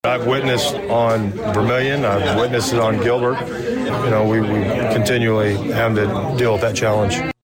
And while Danville Police have said before that the residential area on North Vermilion from Winter to Voorhees can be bad, Police Chief Christopher Yates says the same is indeed true for that part of Gilbert.